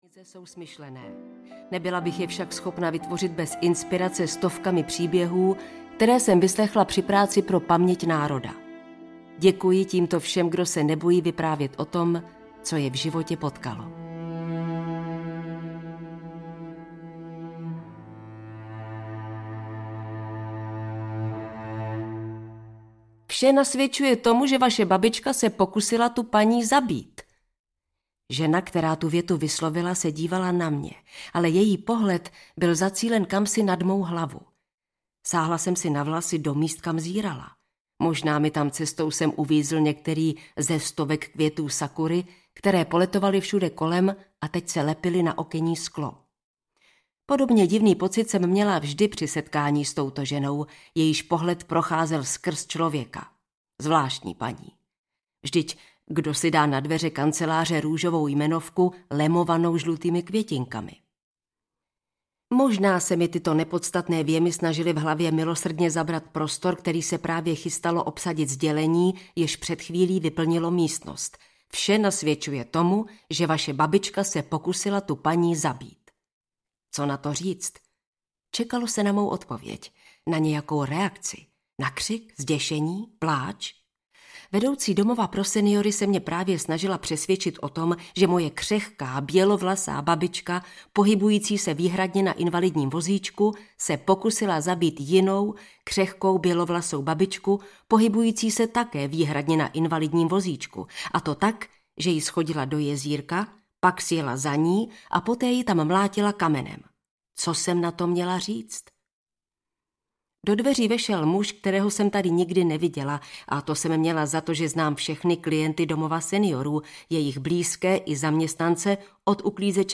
Ty chladné oči audiokniha
Ukázka z knihy